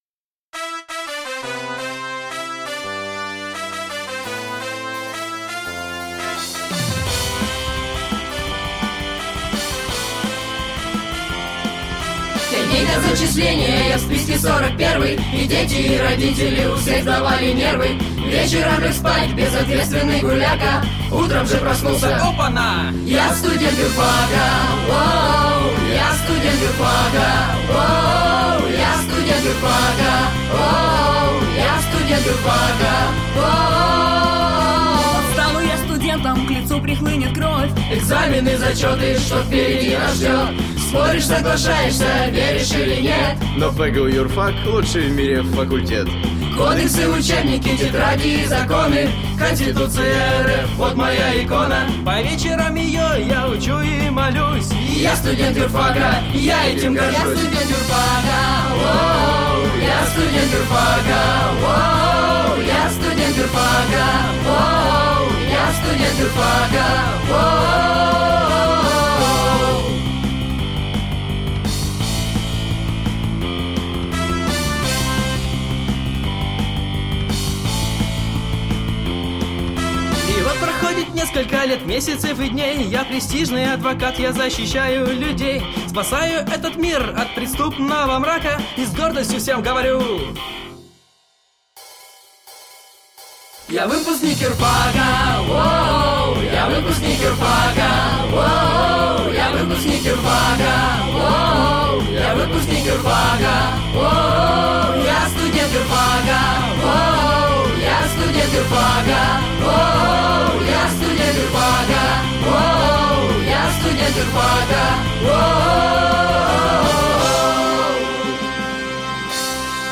В СДК ПГУ состоялась звукозапись «Гимна первокурсников ЮрФака»
И вот, в первый день весны в Студенческом дворце культуры Университета состоялась звукозапись полюбившегося на Юрфаке Гимна первокурсников . Его исполнили несколько лучших голосов юридического факультета и Юридического колледжа при ПГУ